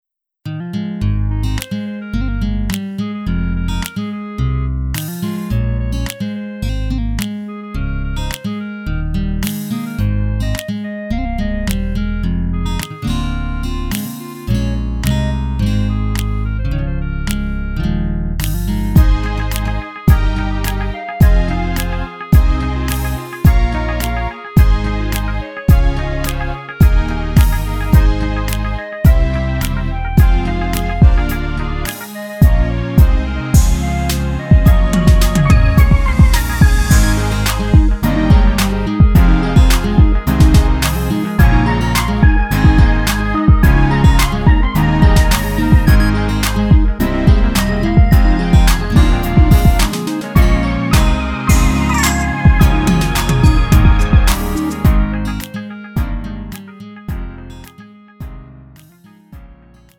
음정 -1키 2:53
장르 구분 Lite MR